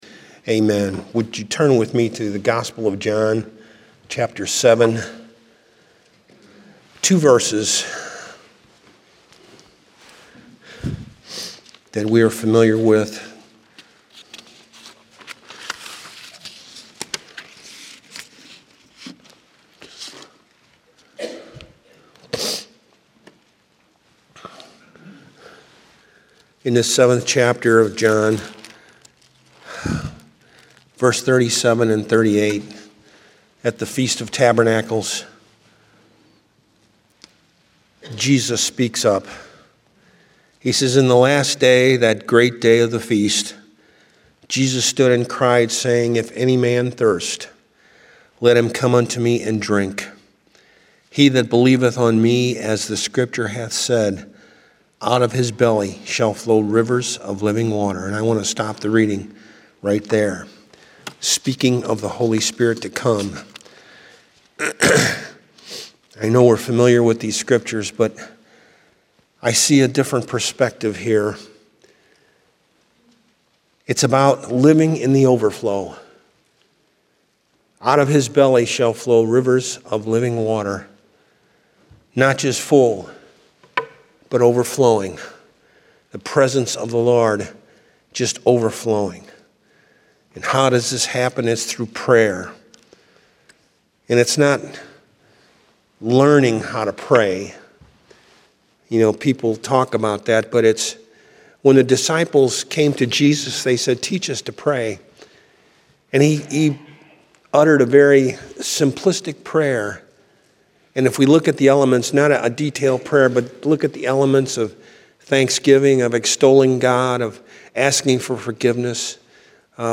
Download Sermon Audio File Evangelical Full Gospel Assembly